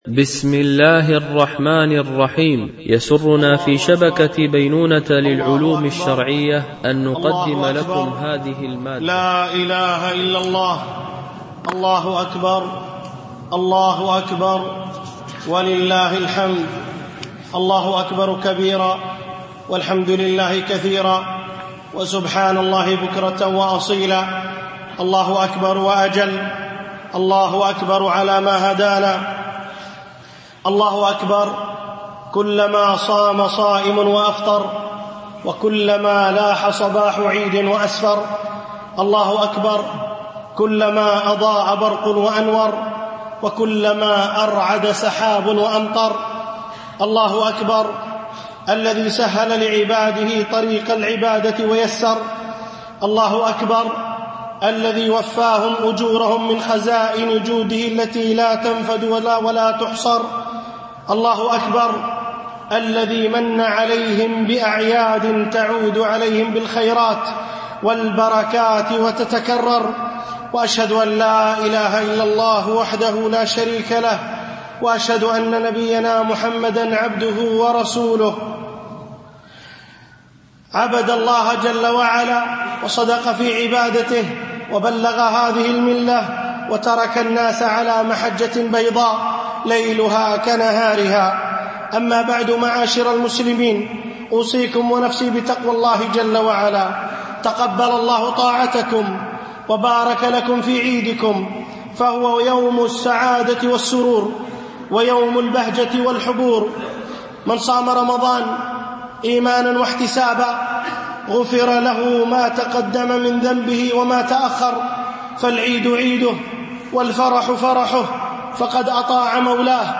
خطبة عيد الفطر (1438 هـ)